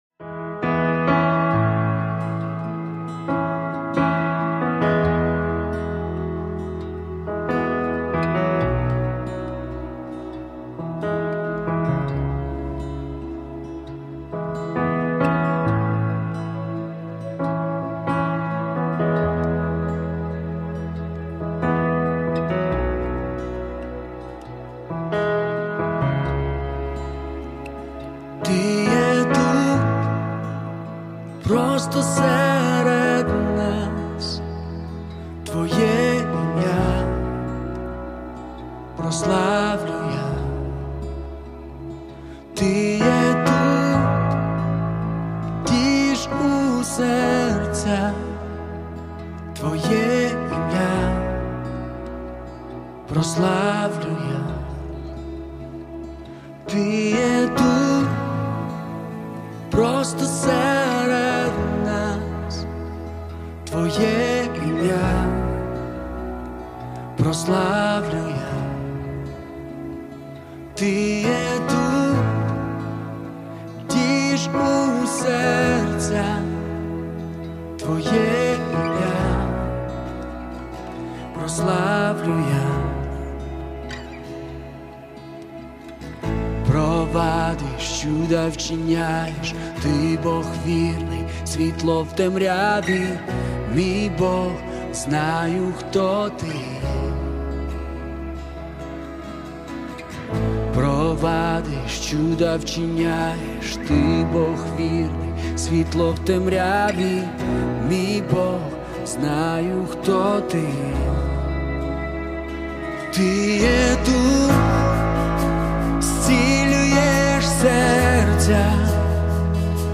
Acoustic live